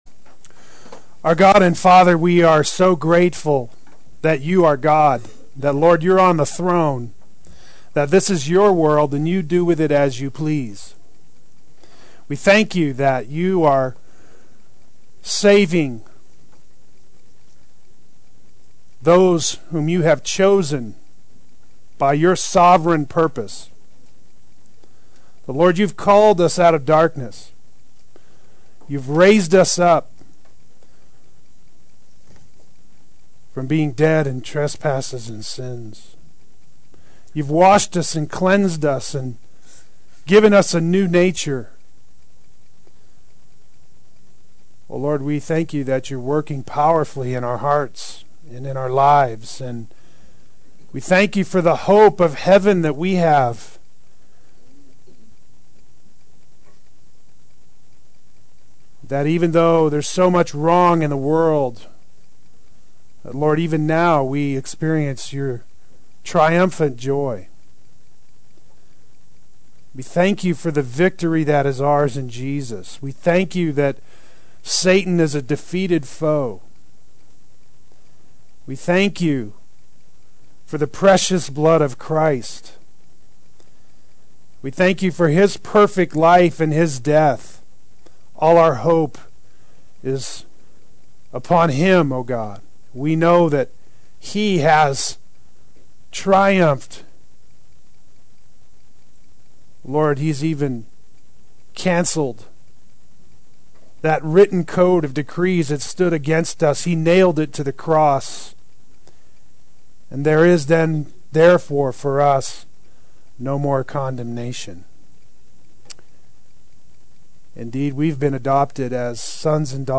Walk in the Spirit Adult Sunday School